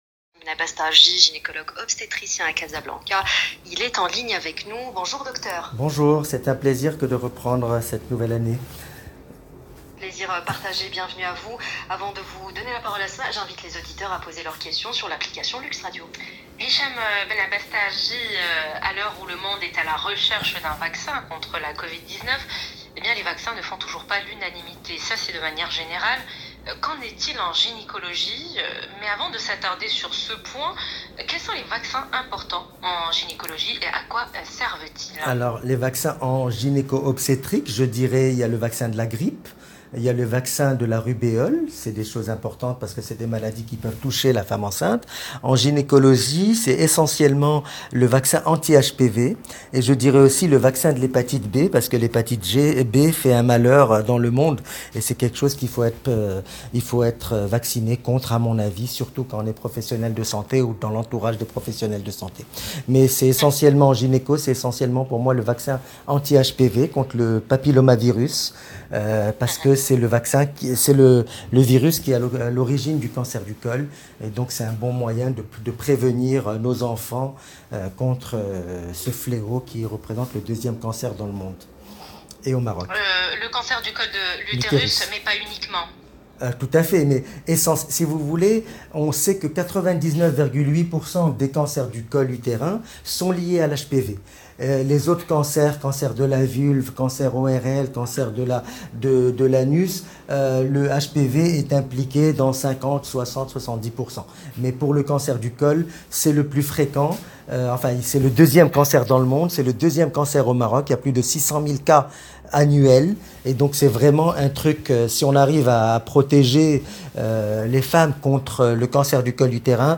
Voyons aussi les autres vaccins a recommander en gynécologie-obstétrique dans cette interview dans l’Heure essentielle sur LUXE RADIO